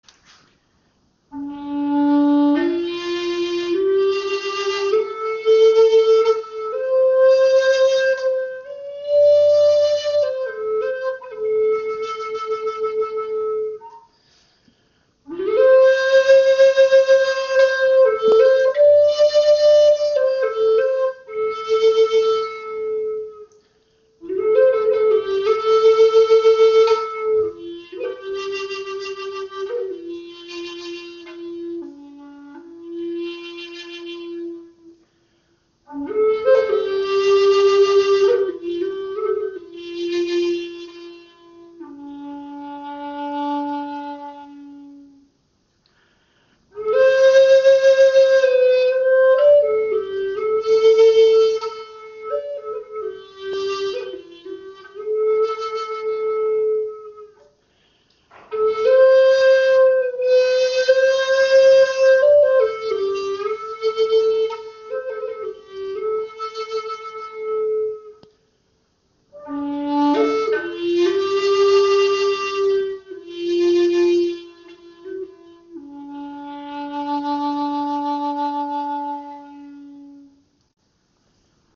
Warmer, tiefer Klang in D-Moll – diese 432 Hz Chakra Flöte aus Walnuss verbindet kreative Energie mit meditativer Ruhe.
• Icon 432 Hz Stimmung – für ein fühlbar harmonisches Klangbild
Diese 432 Hz Chakra Flöte in D-Moll ist aus einem Stück Walnussholz handgefertigt und vereint warme Bassklänge mit der harmonisierenden Wirkung der 432 Hz Stimmung.
Gefertigt aus edlem Walnussholz und aus einem einzigen Stück gearbeitet, entfaltet sie einen warmen, klaren Klang mit brillanter Präsenz in den höheren Tönen.